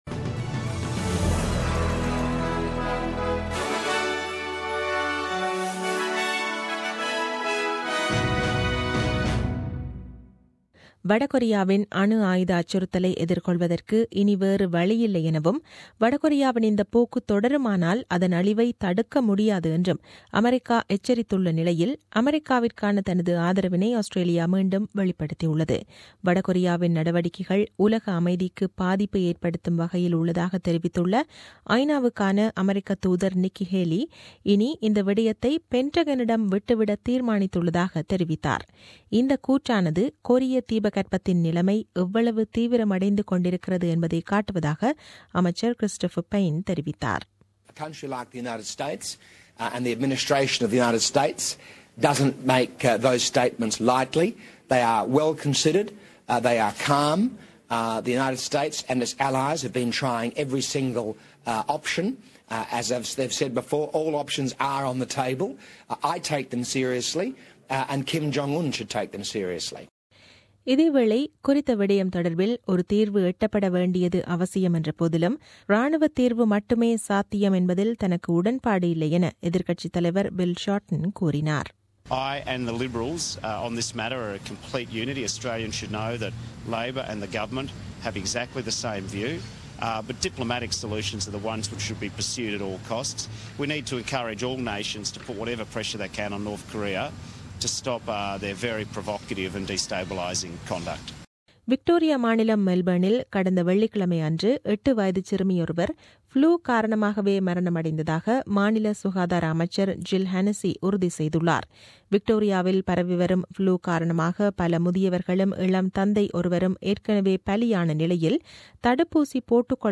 The news bulletin aired on 18 Sep 2017 at 8pm.